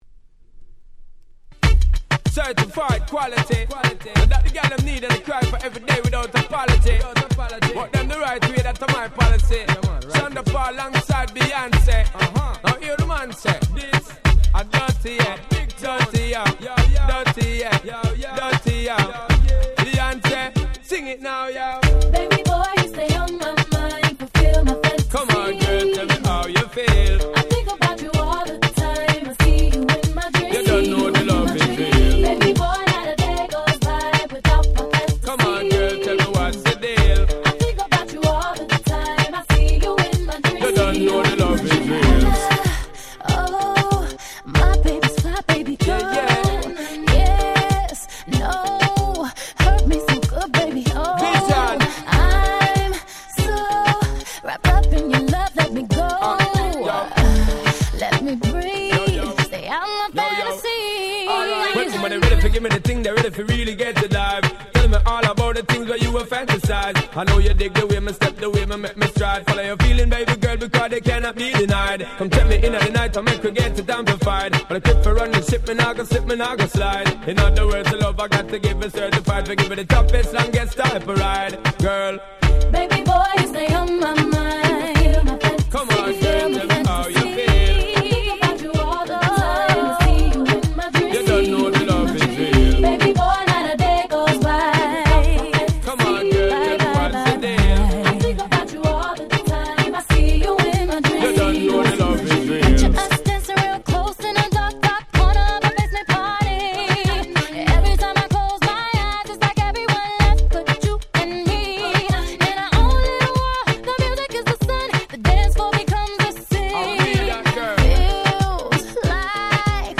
04' Very Nice Mash Up !!
レゲエ ダンスホール 00's R&B